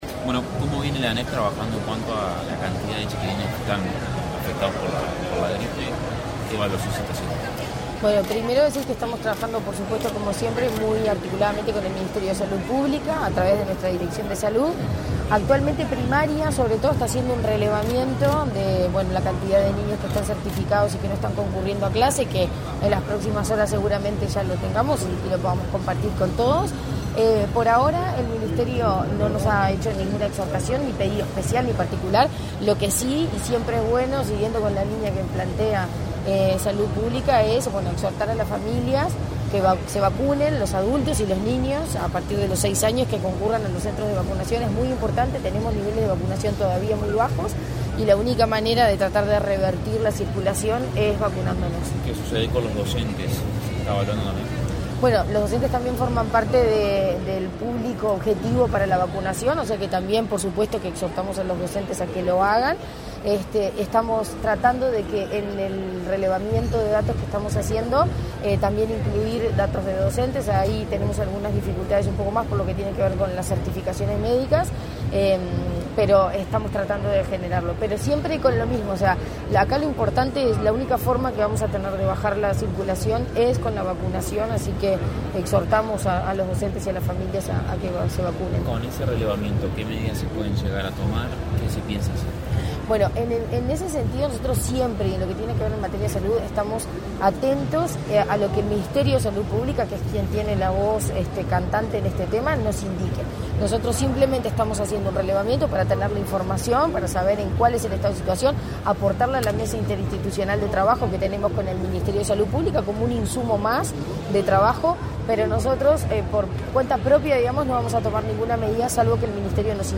Declaraciones a la prensa de la presidenta de la ANEP, Virginia Cáceres
Tras participar, este 28 de mayo, en la firma de un convenio entre Ceibal y la Corporación América Airports, que establece una alianza para el desarrollo de una nueva etapa del programa Ceilab en Canelones, la presidenta de la Administración Nacional de Educación Pública (ANEP), Virginia Cáceres, realizó declaraciones a la prensa.
caceres prensa.mp3